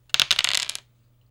効果音
少し効果音を録音しました。